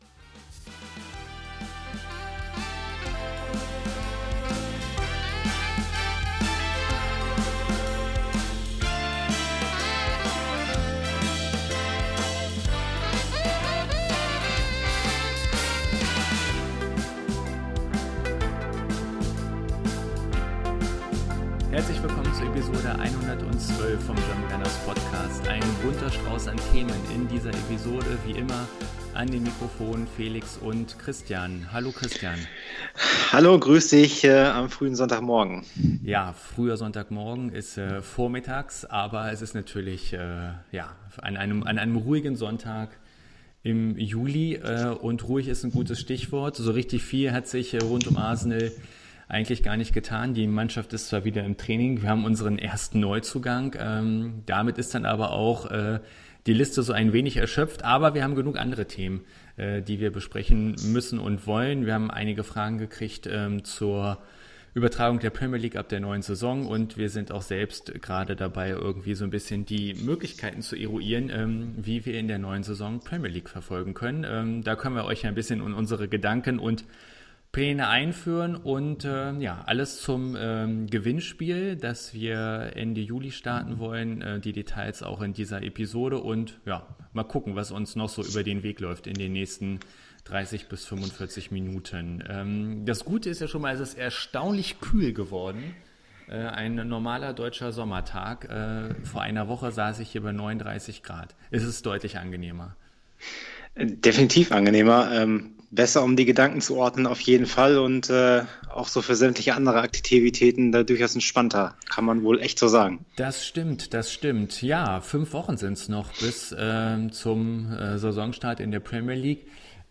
Um am Gewinnspiel teilnehmen zu können müsst Ihr lediglich die Frage beantworten die Euch Marco Hagemann in dieser Episode (ca. Minute 50:20) stellt.